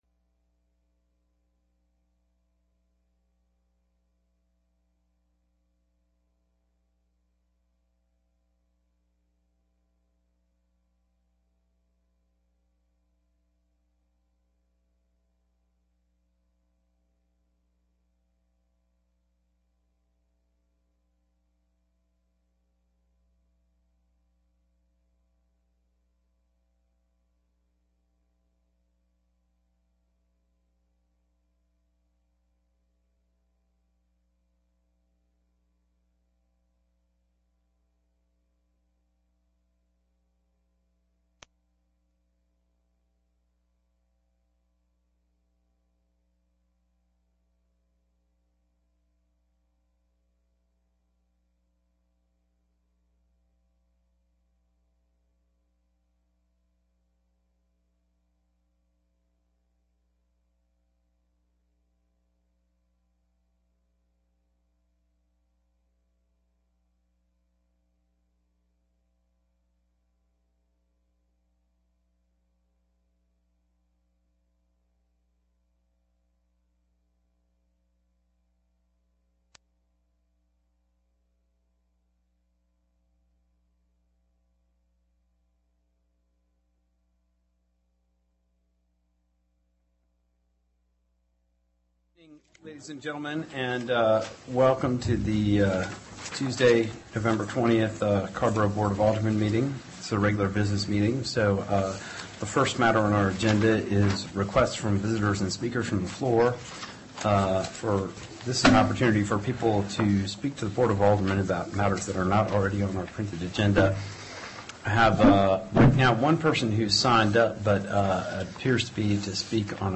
Board Meeting Recording
AGENDA CARRBORO BOARD OF ALDERMEN Regular Meeting Tuesday, November 20, 2012 7:30 P.M., TOWN HALL BOARD ROOM
*Please note that speakers from the floor are requested to limit their comments to three minutes.